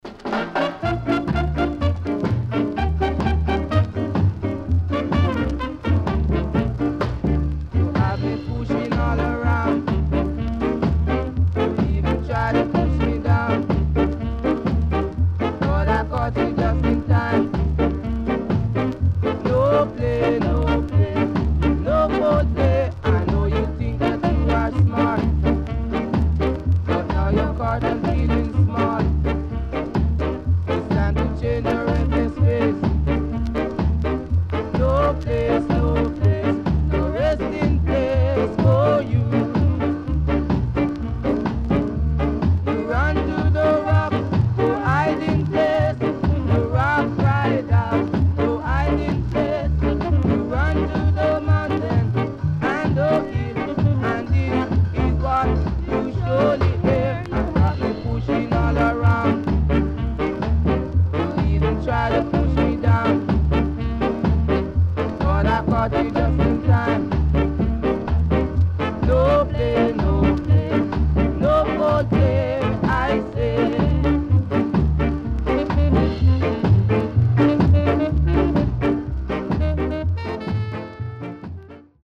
Nice Shuffle Ska Inst & Nice Ska Vocal
SIDE B:全体的にチリノイズがあり、少しプチノイズ入ります。前半から中盤にかけて小傷がありノイズ入ります。